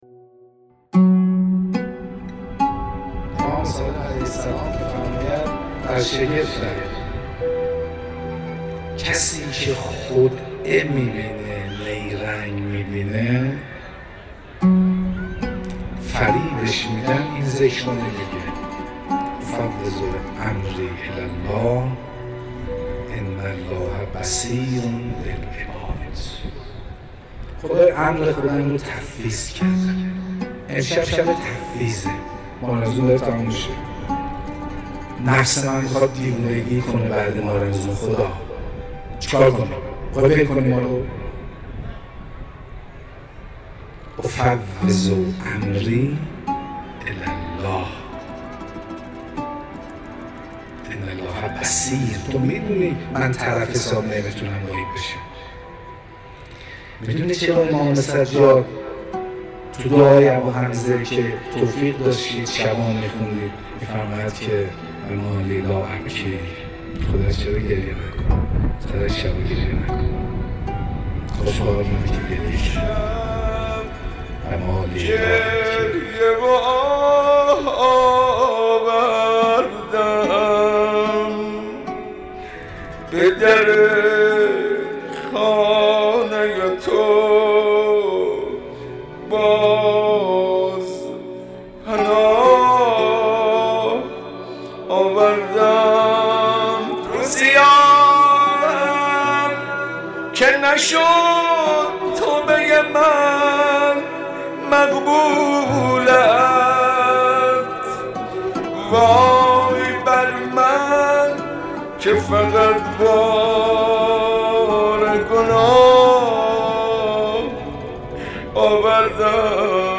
مناجاتی